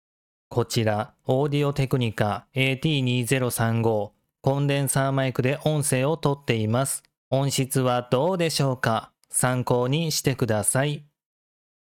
音質チェック！
■ SC8+AT2035コンデンサーマイク